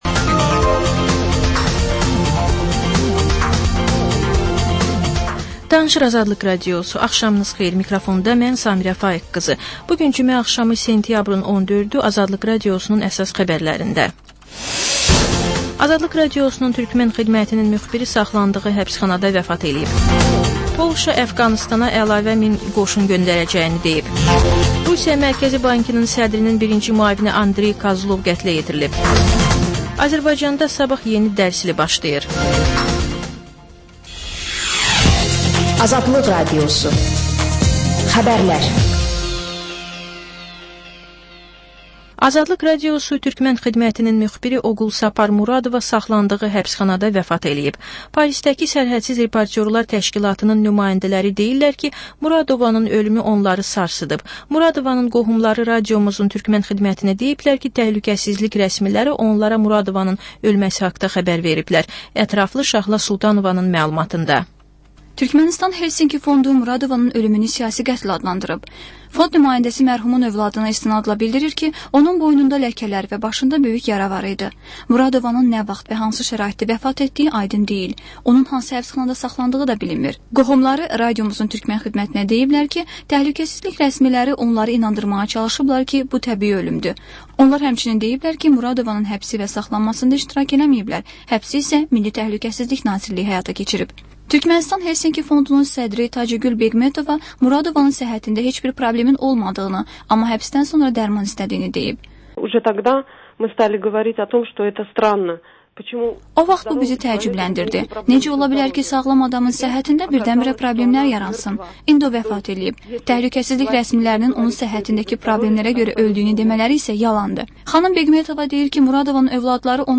Xəbər, reportaj, müsahibə. Sonra: Və ən son: Qlobus: xaricdə yaşayan azərbaycanlılar.